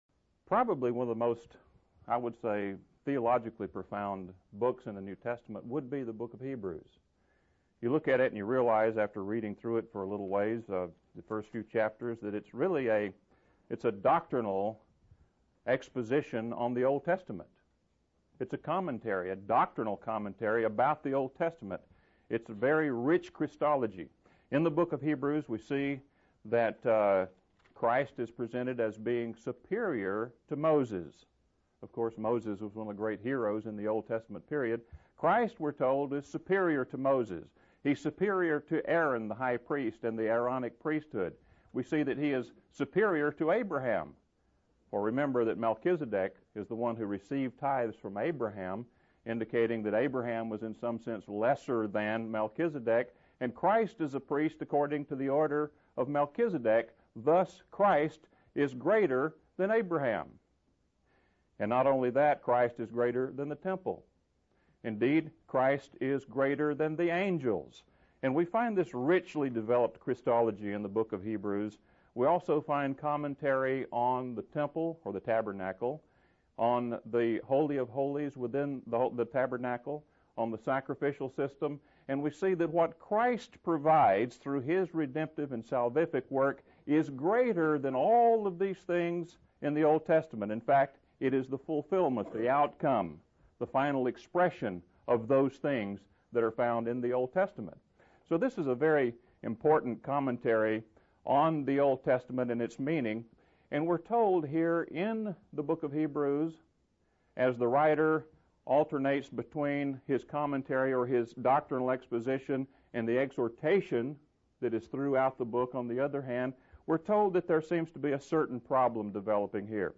Index of /media/sermons/Series/CSFT